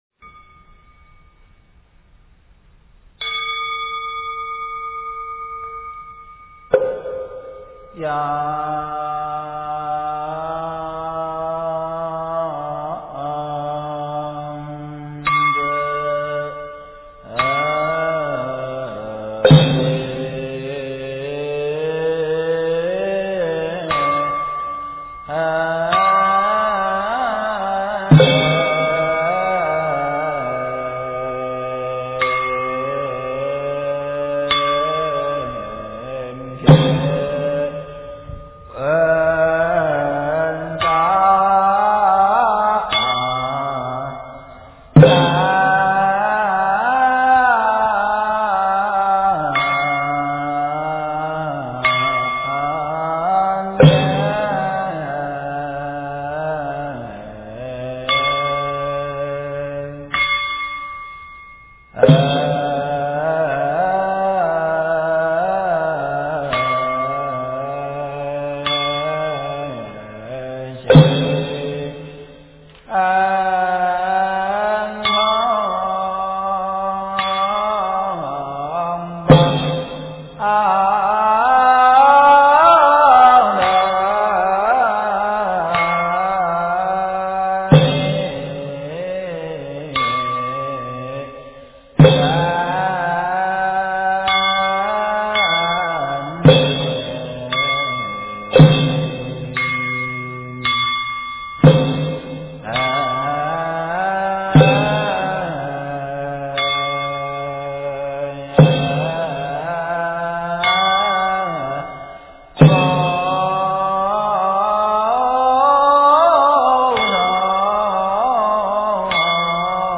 杨枝净水赞--天籁之音 经忏 杨枝净水赞--天籁之音 点我： 标签: 佛音 经忏 佛教音乐 返回列表 上一篇： 召请各类孤魂同赴法会--群星 下一篇： 莲师心咒--昌列寺 相关文章 献供赞（唱诵）--文殊院 献供赞（唱诵）--文殊院...